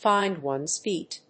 アクセントfínd one's féet